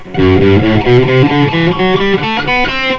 Ok, here is the tab, just a 12 note ascending run in E Natural Minor from the F note.
All up's